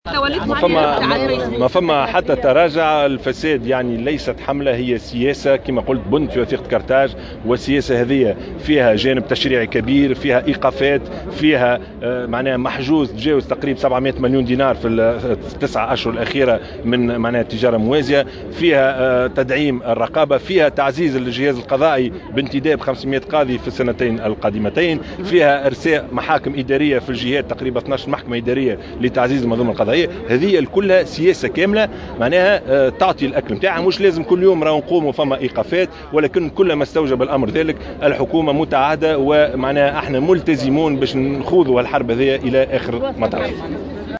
وقال في تصريح لـ "الجوهرة أف أم" إن سياسة مكافحة الفساد هي أحد بنود وثيقة قرطاج.